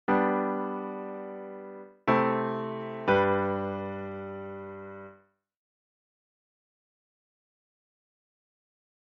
Se escuchan 3 acordes. El primero es la triada de la tonalidad. Los que siguen son los de la cadencia.
Plagal+Ii+A+I 6+G (audio/mpeg)